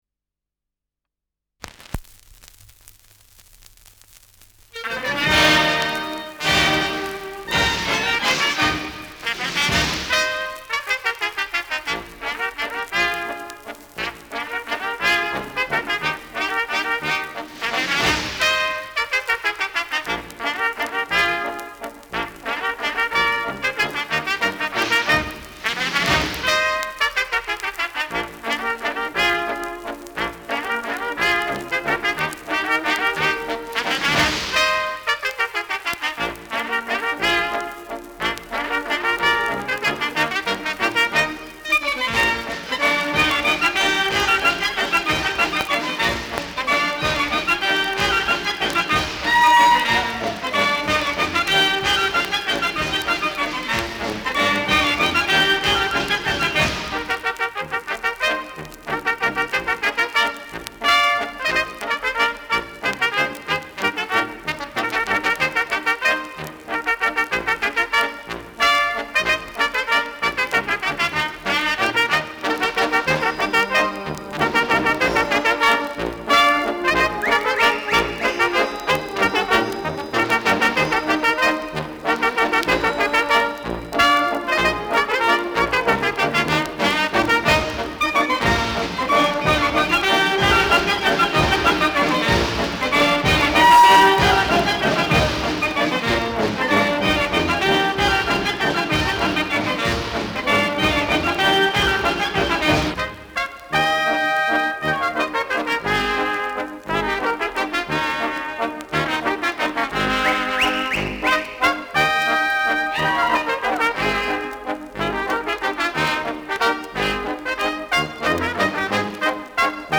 Schellackplatte
Leichtes Grundknistern : Vereinzelt leichtes Knacken